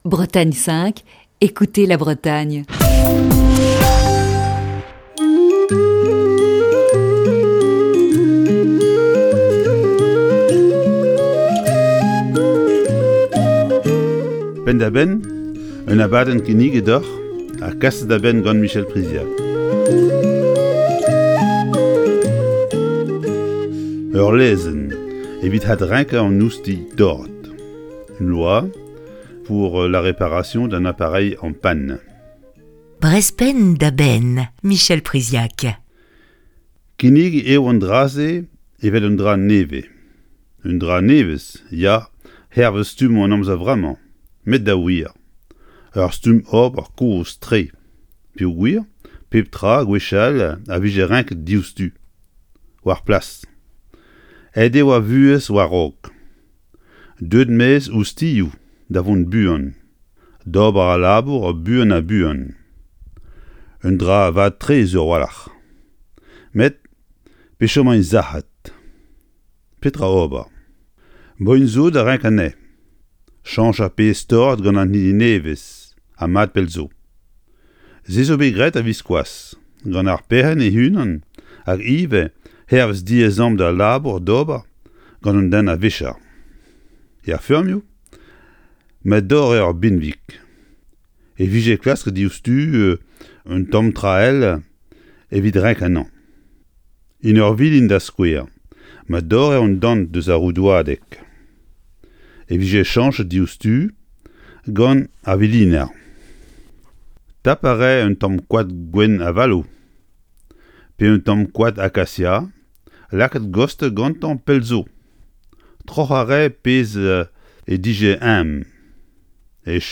Chronique du 18 janvier 2021.